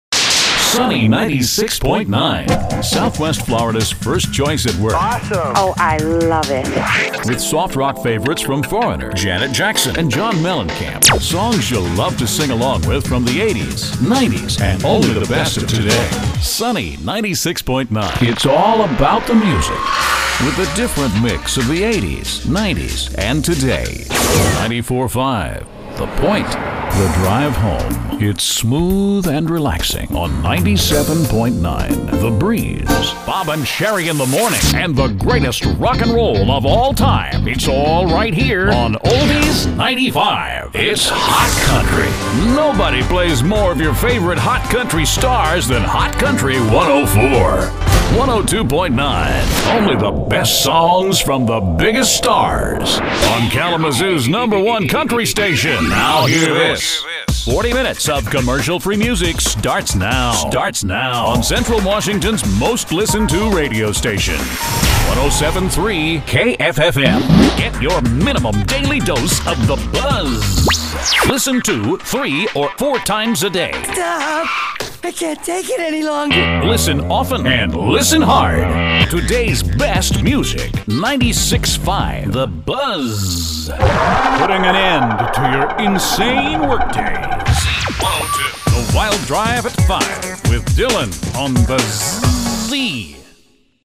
Radio Jingles, Radio Imaging - Radio Sweepers, ID's and Promos
Male Voice Over Talent